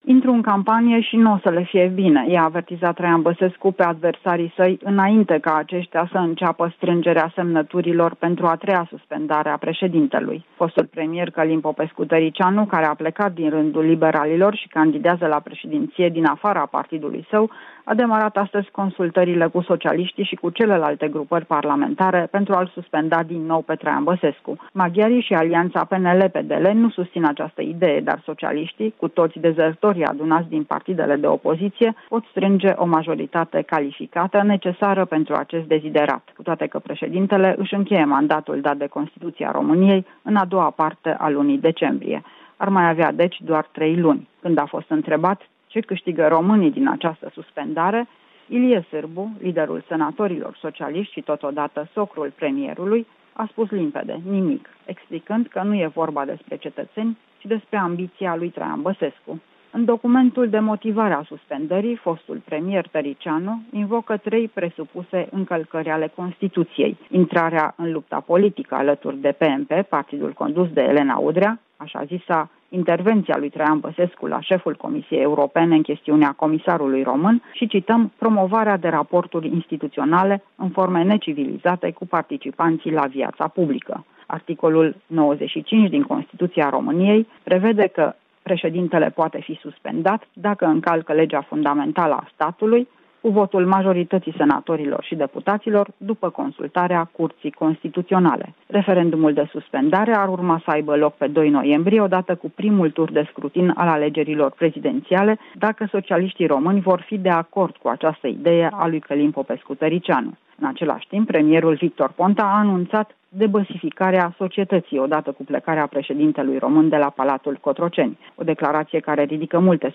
Corespondenţa zilei de la Bucureşti.